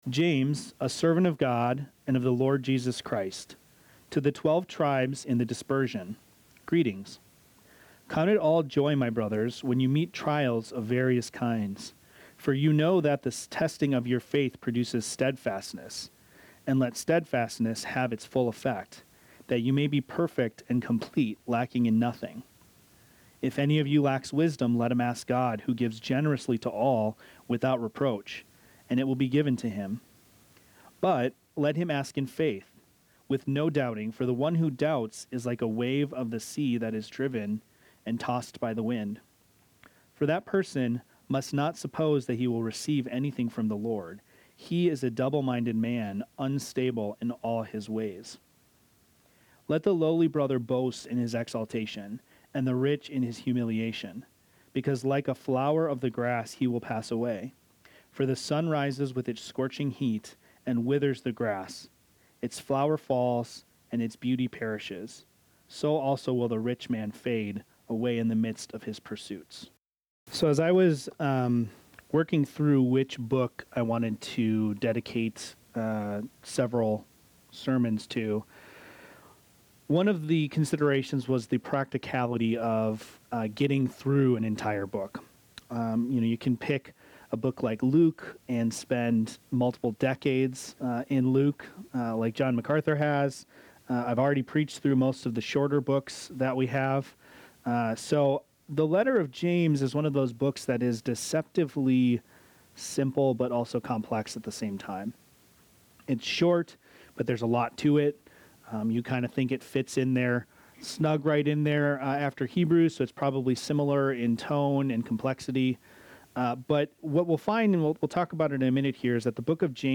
This sermon, titled “The Wisdom of Trials,” dives into James 1:1–11, exploring the profound truth that God uses trials to authenticate and strengthen our faith.